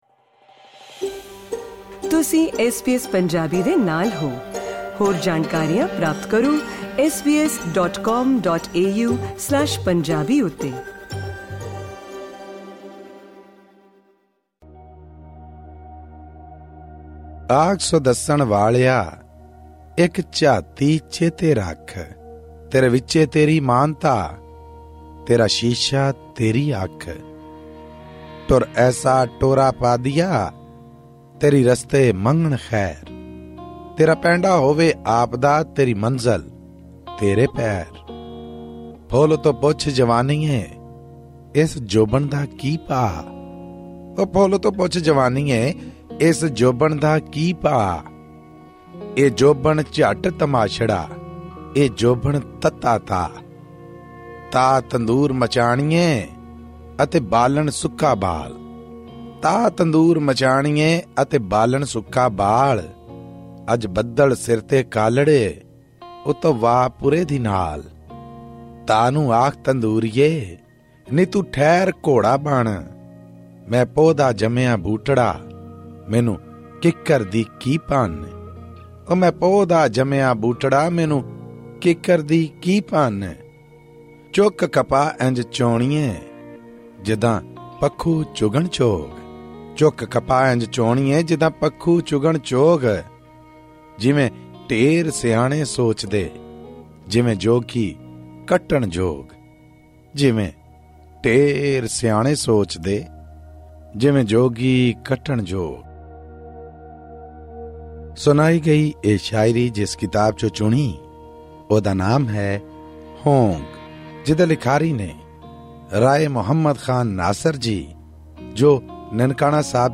Pakistani Punjabi poetry book review: 'Hoong' by Rai Muhammad Khan Nasir